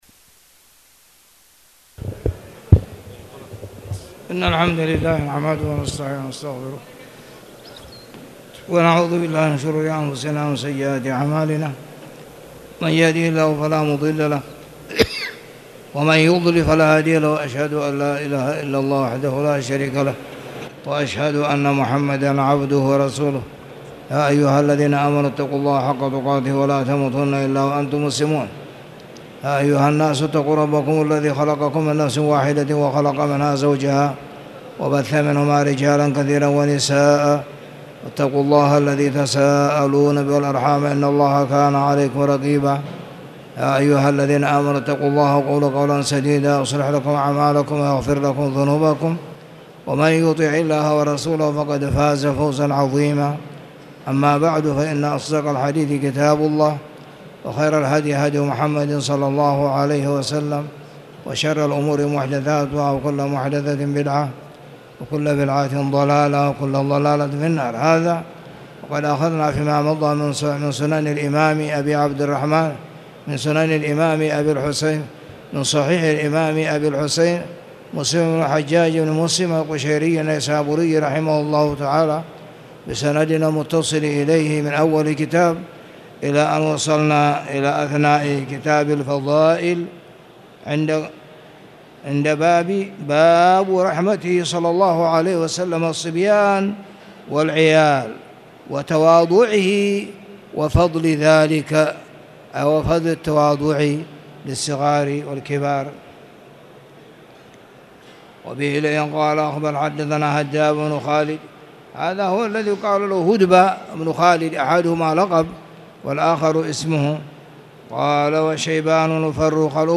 تاريخ النشر ١٧ ذو الحجة ١٤٣٨ هـ المكان: المسجد الحرام الشيخ